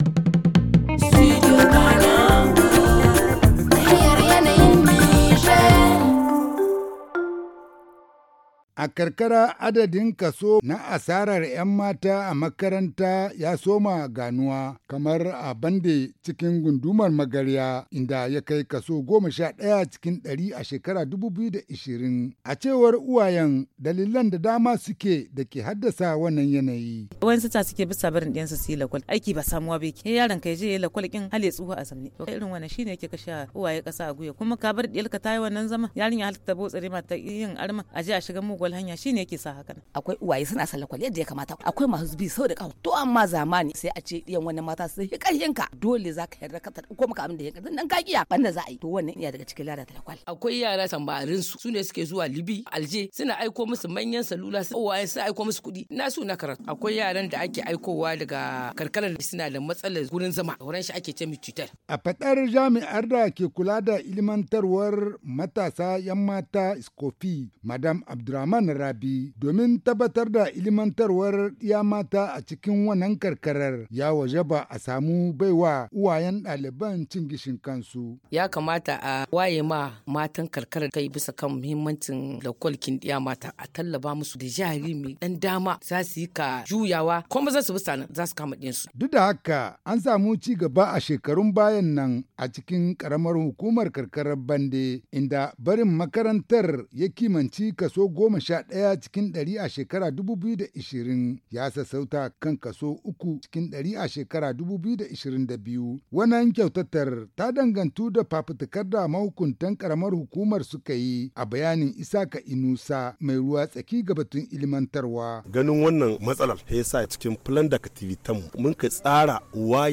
La déperdition scolaire des jeunes filles est une réalité dans plusieurs zones rurales du Niger. À Bandé par exemple, dans la région de Zinder, malgré les avancées enregistrées ces dernières années dans le maintien des jeunes filles à l’école, beaucoup abandonnent pour plusieurs raisons évoquées dans ce reportage